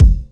Kick Soulesque 6.wav